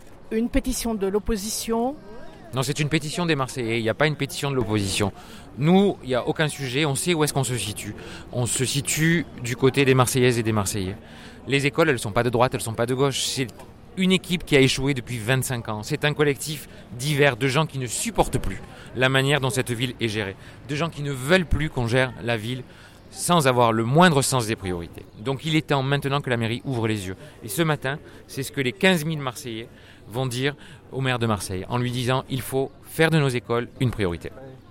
C’est par une manifestation des enseignants, parents d’élèves, architectes… que les élus de Marseille ont été accueillis avant la remise au Conseil municipal de la pétition pour le PRE (Plan de rénovation des écoles) marseillaises qui compte plus de 15 000 signataires. son_copie_petit-338.jpgParmi les manifestants, des élus d’opposition dont Benoît Payan, président du groupe socialiste au conseil municipal.